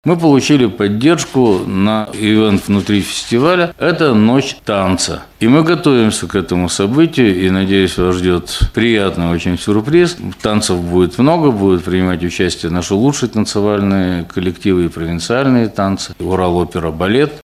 на пресс-конференции «ТАСС-Урал».